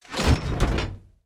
door_start.ogg